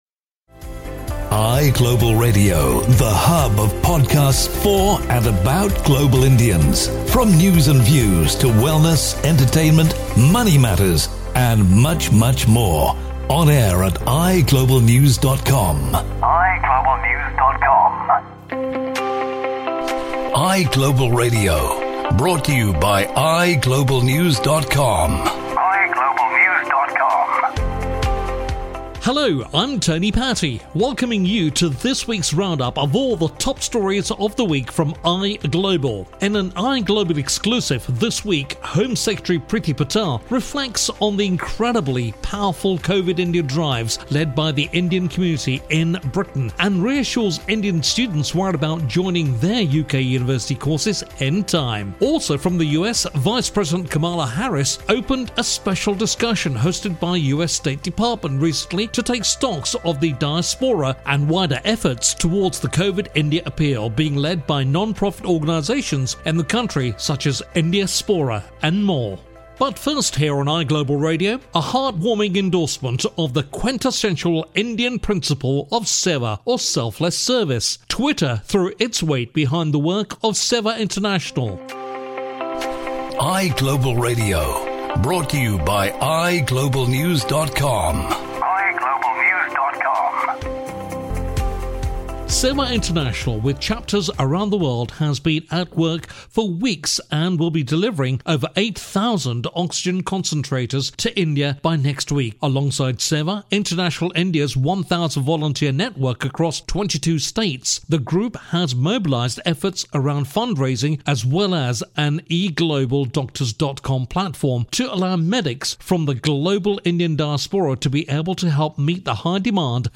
From an exclusive interview with UK Home Secretary Priti Patel and a special message from US Vice-President Kamala Harris to some Travel Tales and exercise tips.